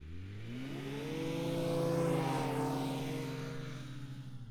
Internal Combustion Subjective Noise Event Audio File - Run 1 (WAV)